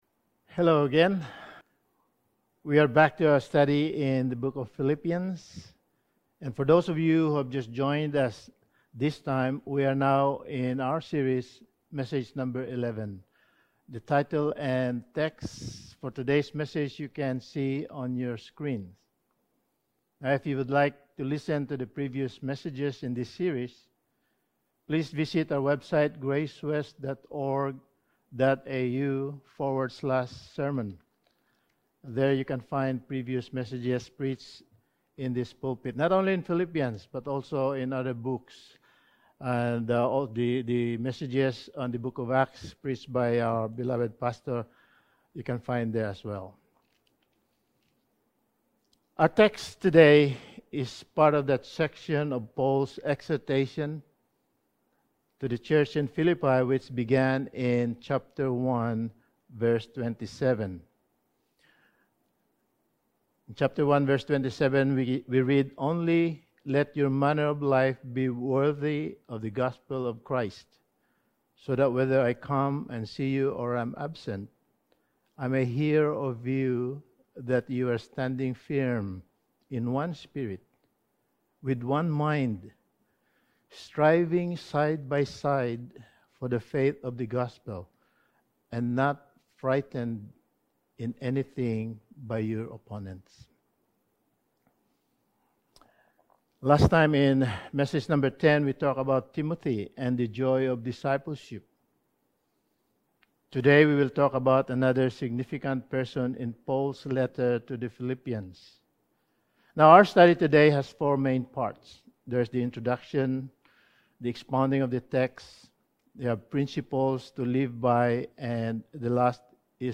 Passage: Philippians 2:25-30 Service Type: Sunday Morning